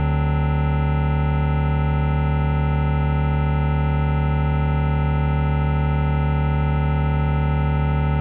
d7-chord.ogg